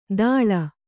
♪ dāḷa